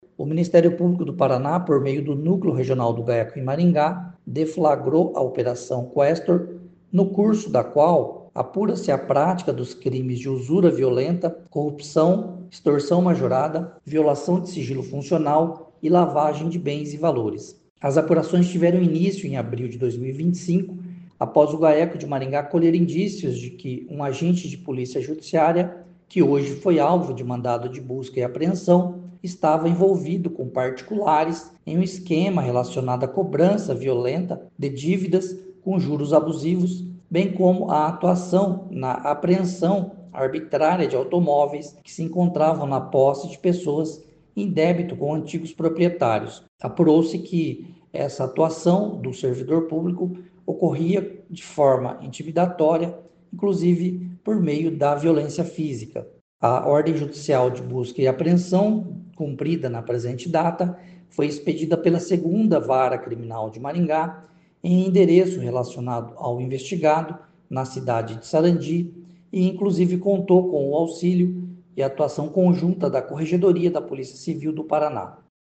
Ouça o que diz o promotor de Justiça Marcelo Alessandro Gobbato: